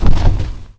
bullet_hit_object.wav